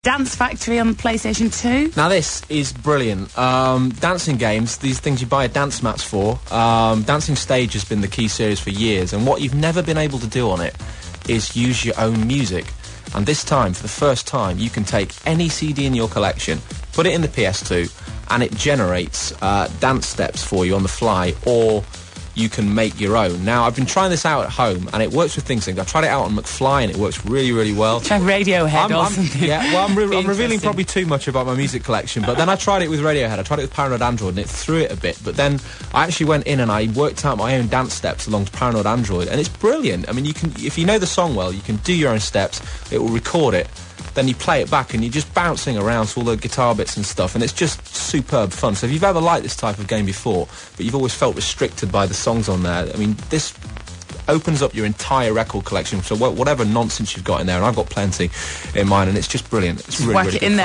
Full review (MP3 audio format, 350K)
Review64kbit.mp3